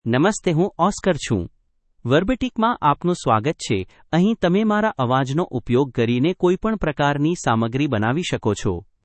OscarMale Gujarati AI voice
Oscar is a male AI voice for Gujarati (India).
Voice sample
Listen to Oscar's male Gujarati voice.
Male
Oscar delivers clear pronunciation with authentic India Gujarati intonation, making your content sound professionally produced.